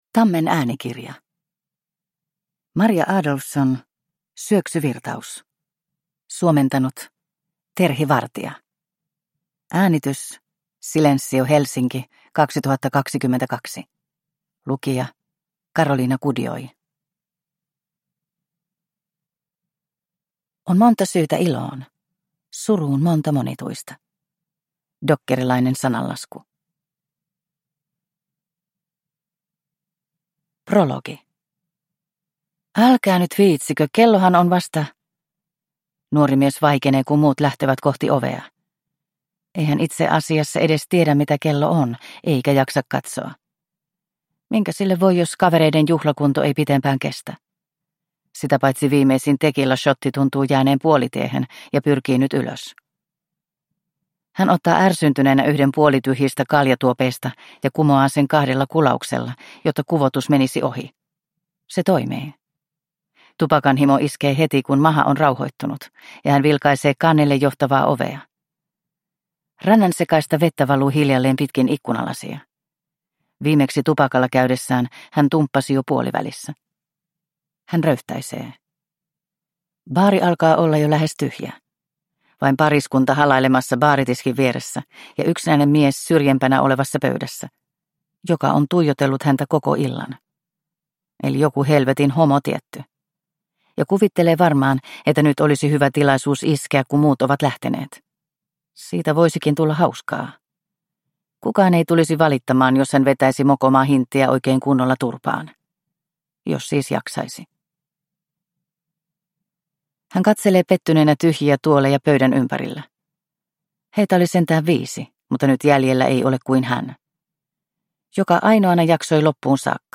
Syöksyvirtaus – Ljudbok – Laddas ner